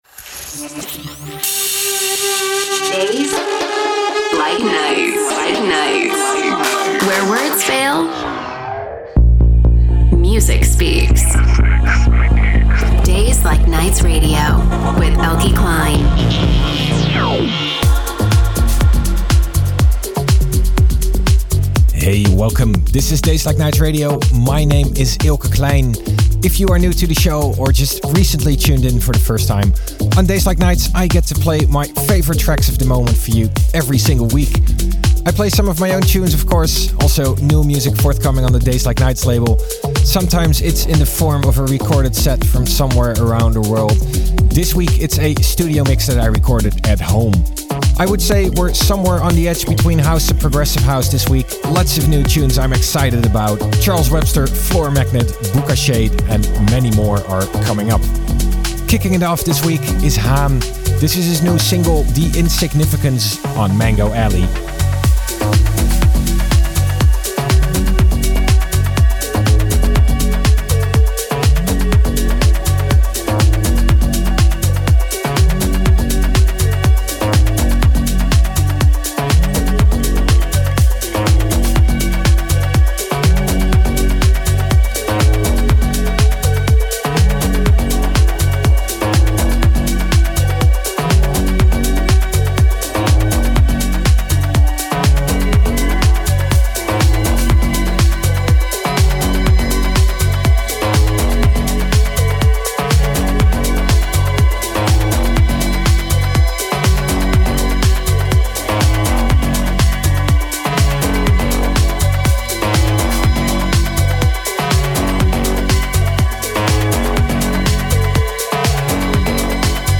Live Set